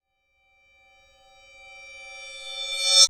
time_warp_reverse_high_02.wav